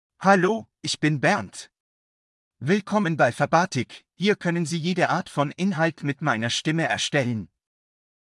Bernd — Male German (Germany) AI Voice | TTS, Voice Cloning & Video | Verbatik AI
Bernd is a male AI voice for German (Germany).
Voice sample
Listen to Bernd's male German voice.
Male